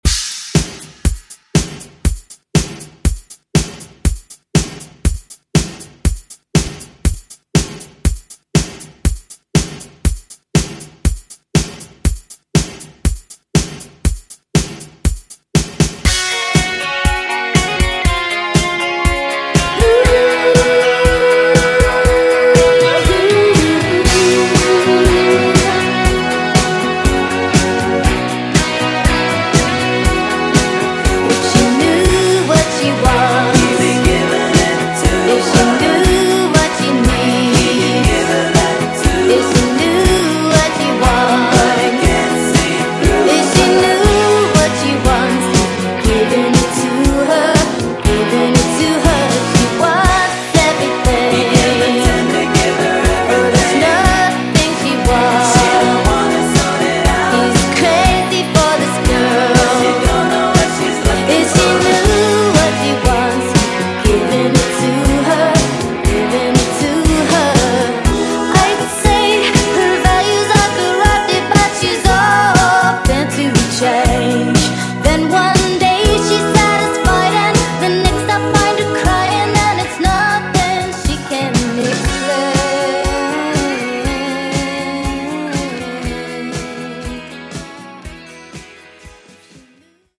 80s PopRock ReDrum)Date Added